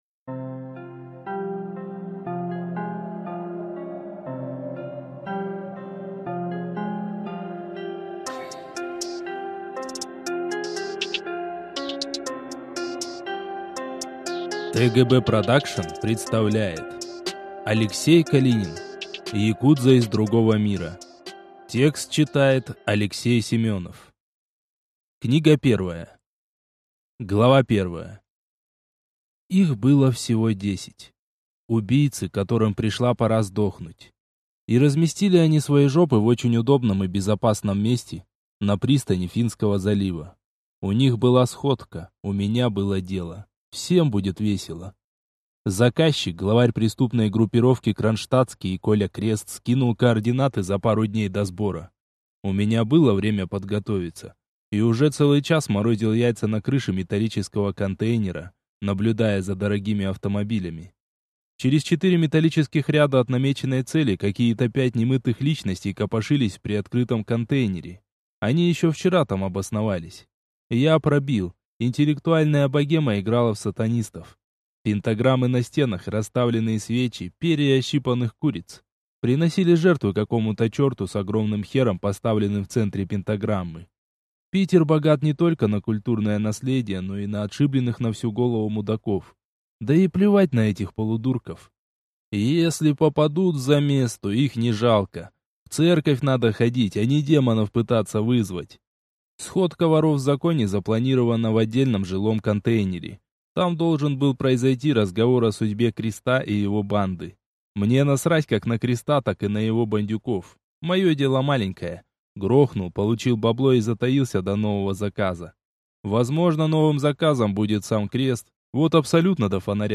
Аудиокнига Якудза из другого мира | Библиотека аудиокниг